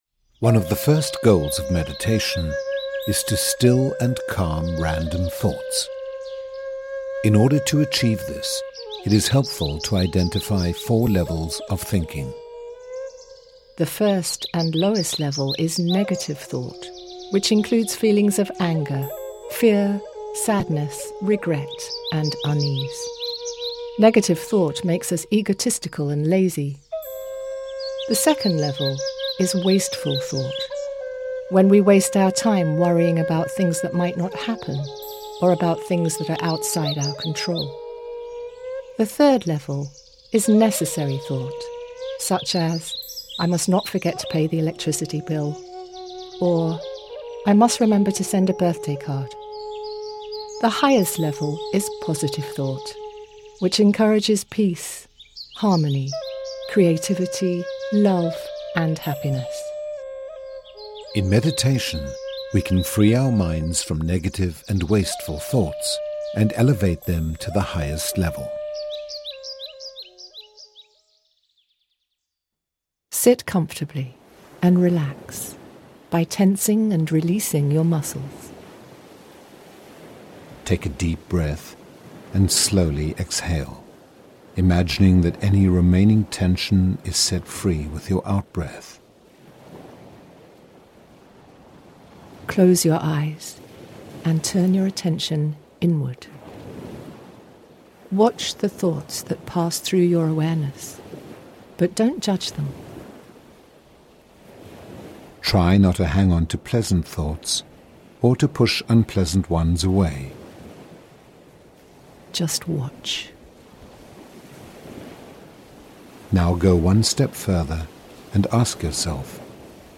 Professional British VO Artist, (multi-genre) with a broadcast-quality home studio.
English - United Kingdom
Middle Aged